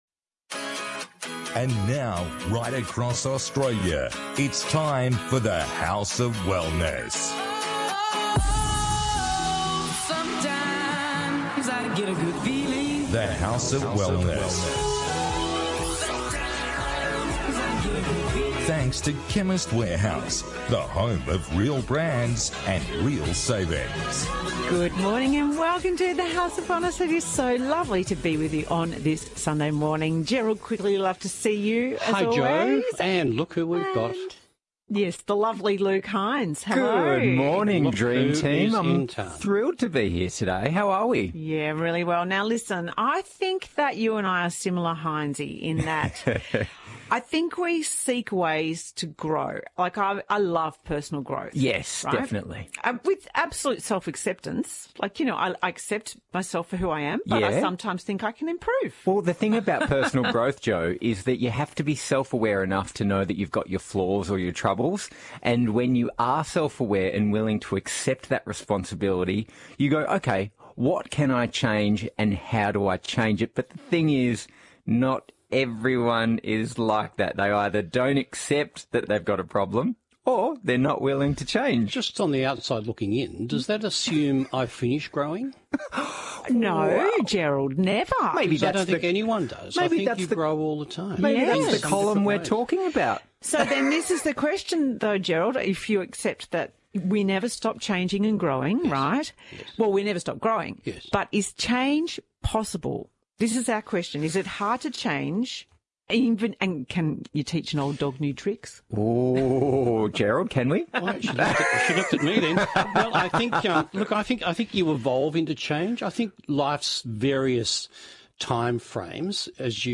How “brain herb” gingko biloba may help with cognitive performance, memory and blood circulation as you age. Listener calls, including questions around dizziness after a bone break, bruising, the gut microbiome, Baker’s cyst and processed meat.